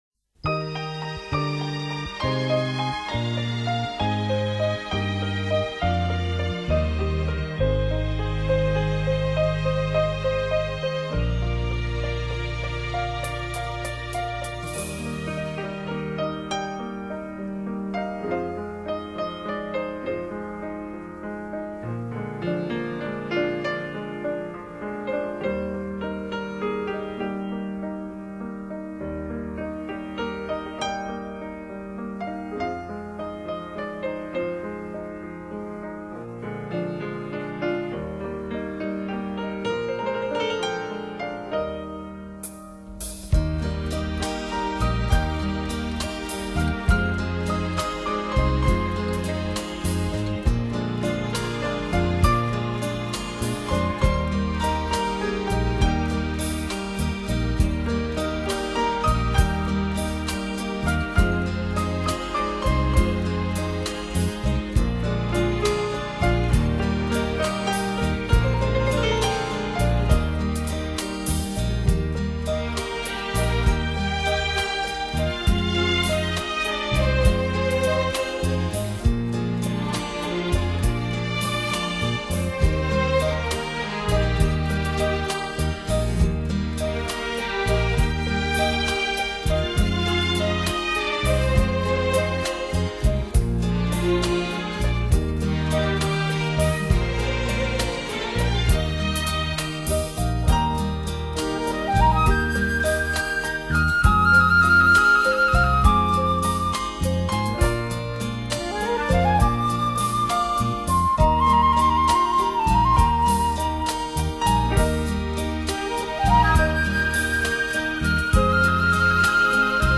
这是一种治疗音乐，也是一种美妙音乐。